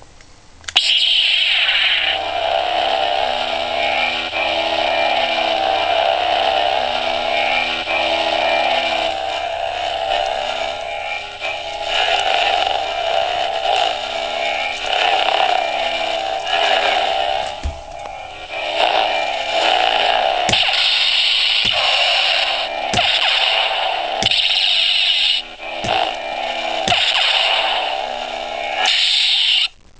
アバウトに音種を解説：起動＝光刃が出る音。駆動＝ブ〜ンというハム音。移動＝振った時に鳴るヴォ〜ンという音。衝撃＝刃をぶつけた時に鳴るバシ〜ンという音。収縮＝光刃が消える音。